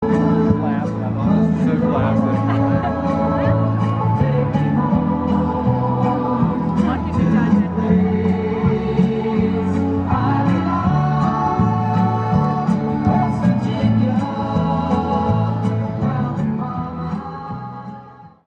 Yesterday, we attended the graduation ceremony at our university.
GraduationIntroMusic.mp3